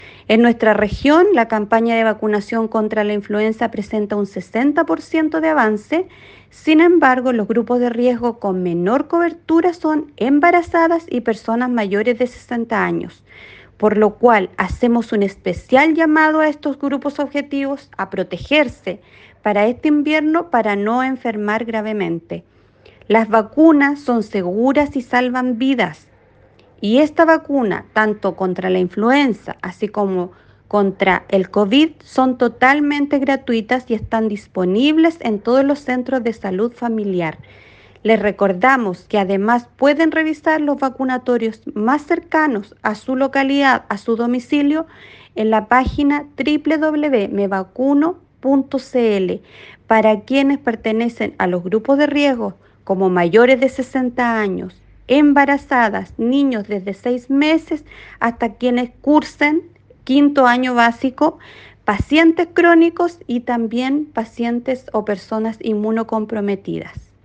La influenza, el rinovirus y la parainfluenza son los virus que mantienen la más alta circulación, por lo que las autoridades hacen  un  llamado a la vacunación para evitar complicaciones y riesgo de hospitalización, como indicó la Seremi de Salud Karin Solís.